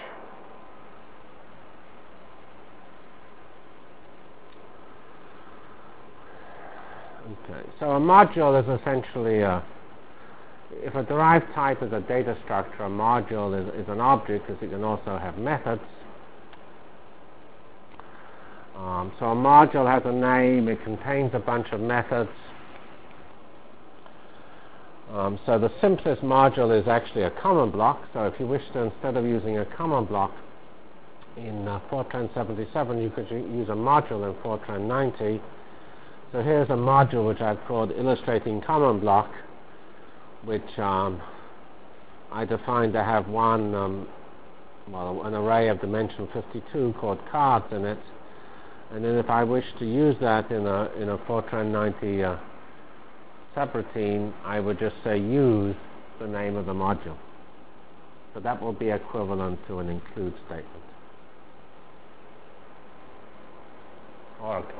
From CPS615-Introduction to F90 Features, Rationale for HPF and Problem Architecture Delivered Lectures of CPS615 Basic Simulation Track for Computational Science -- 24 September 96.